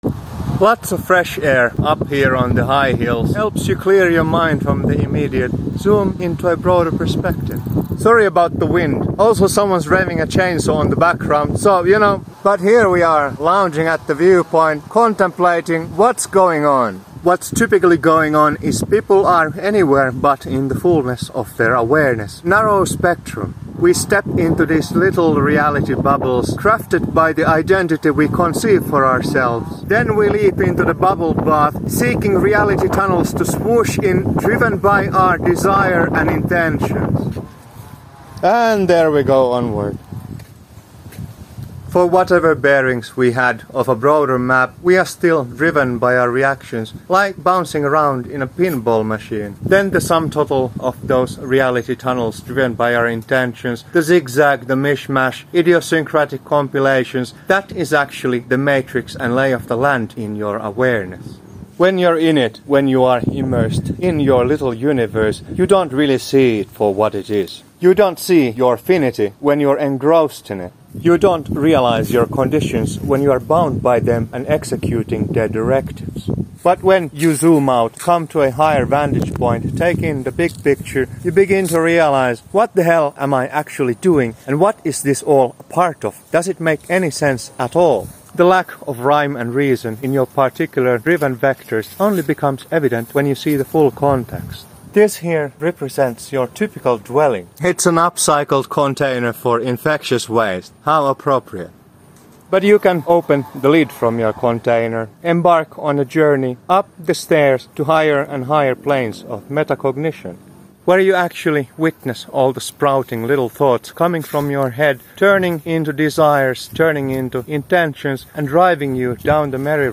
Fresh air on the high hills.